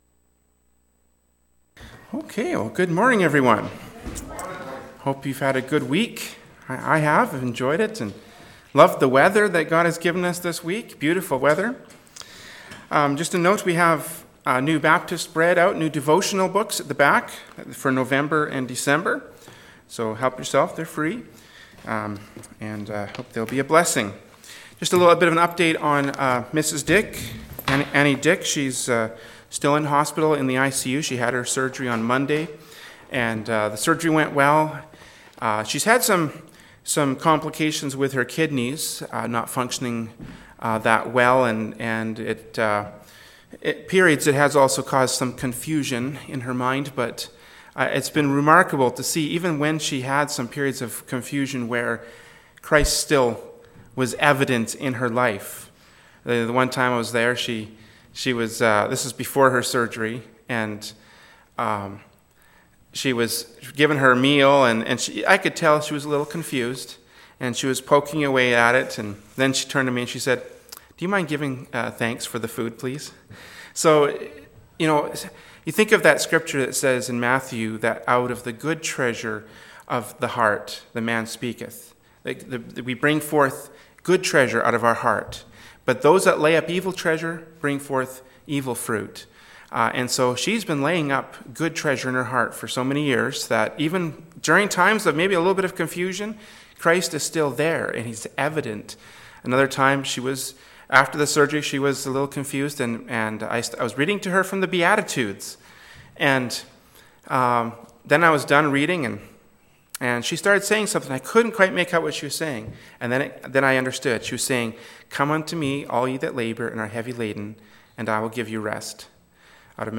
“Proverbs 18:19-24” from Sunday School Service by Berean Baptist Church.